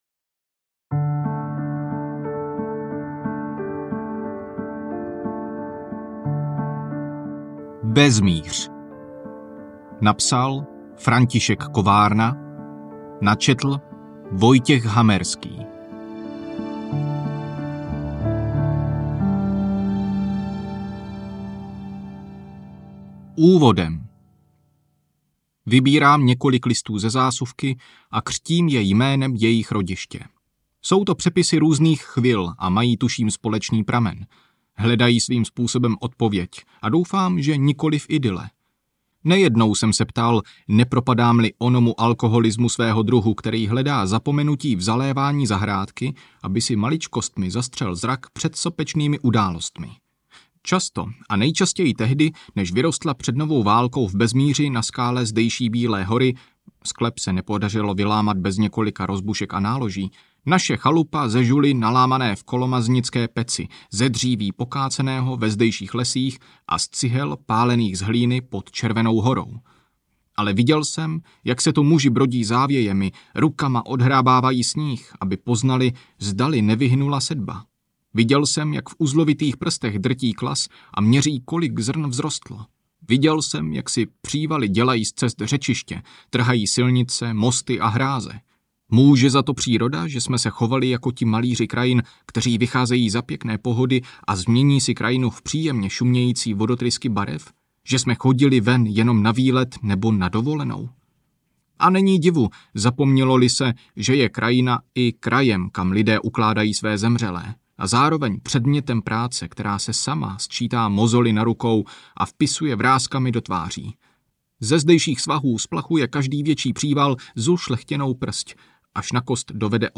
Bezmíř audiokniha
Ukázka z knihy